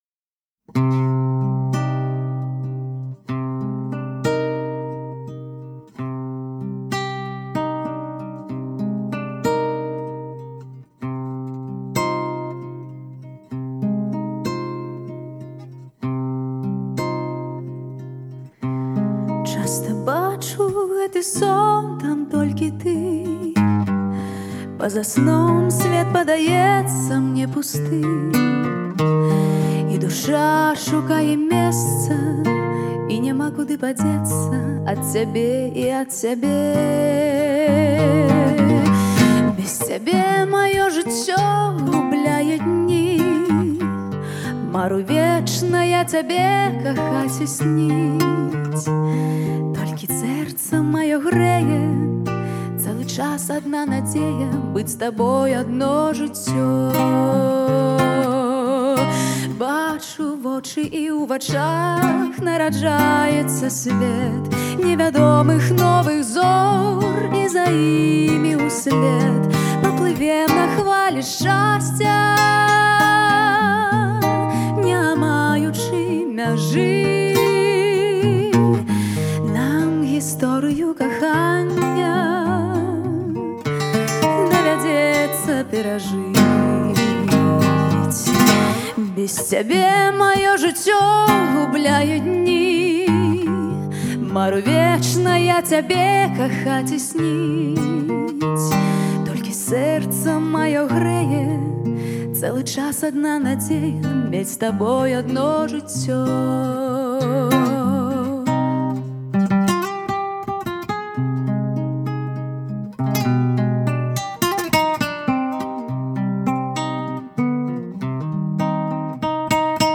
Знакамітая баляда